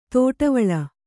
♪ tōṭavaḷa